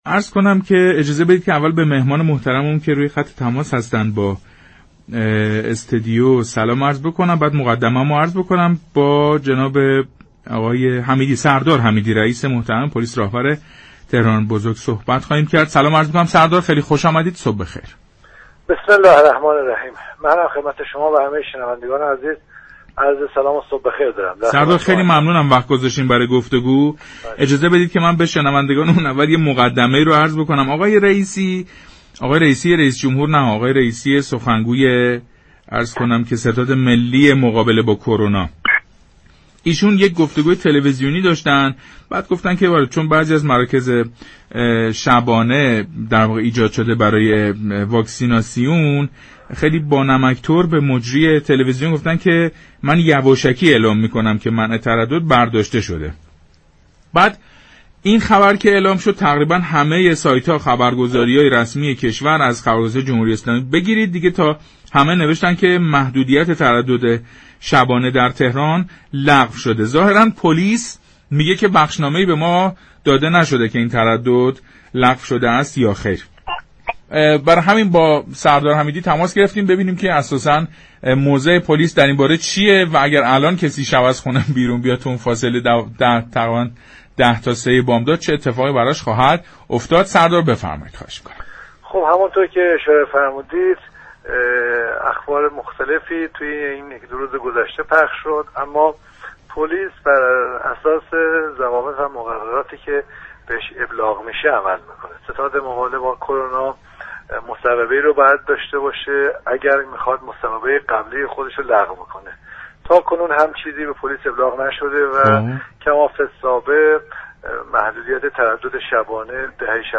سردار محمدحسین حمیدی رئیس پلیس راهنمایی و رانندگی تهران بزرگ در گفتگو با پارك شهر رادیو تهران